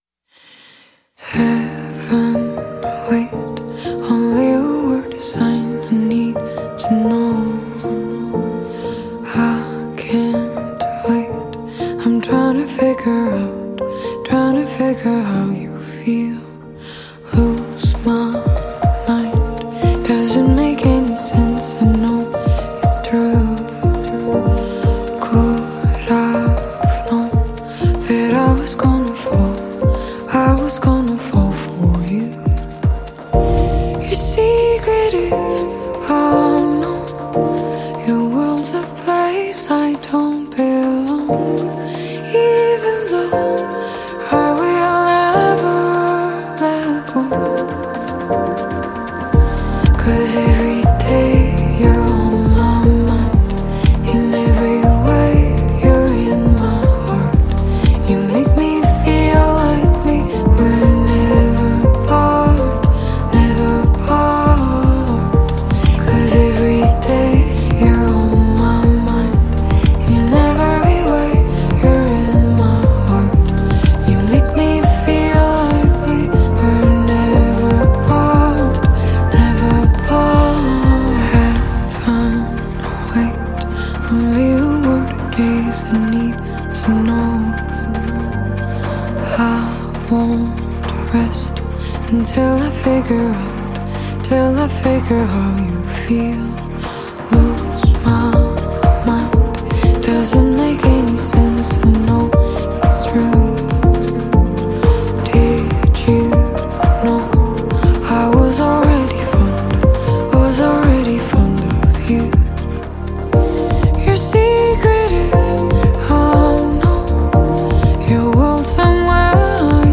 آهنگ غمگین خارجی